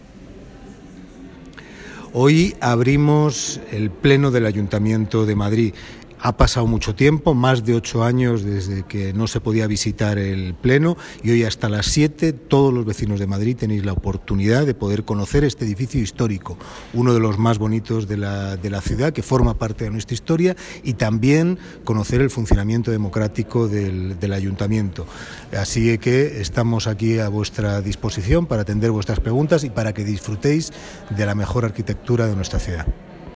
Esta mañana, la Casa de la Villa ha abierto sus puertas a la ciudadanía después de ocho años. Lo ha hecho con motivo de la celebración de la Jornada de Puertas Abiertas del Pleno.
Audio Tercer Teniente de Alcalde del Ayuntamiento de Madrid, Mauricio Valiente.